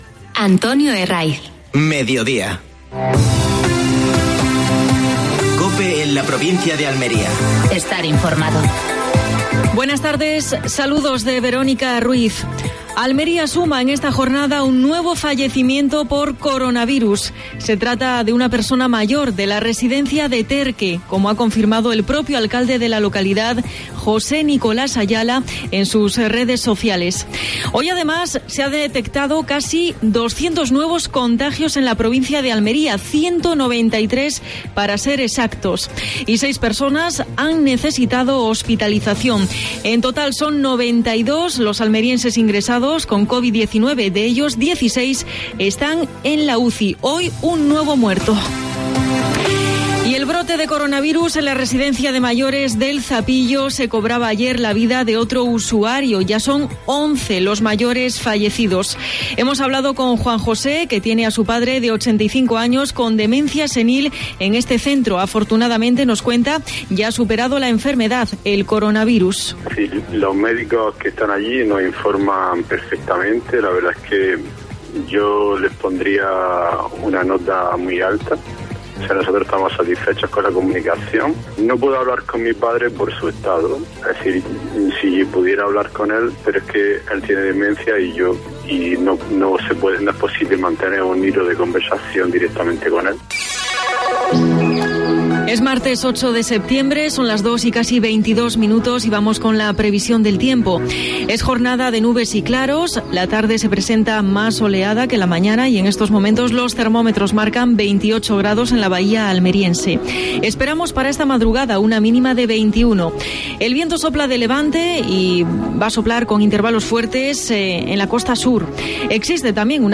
AUDIO: Última hora en Almería. Noticias.